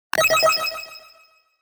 昔懐かしいゲームの冒険の一部のような効果音の爆風音。